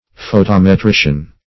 Photometrician \Pho*tom`e*tri"cian\, Photometrist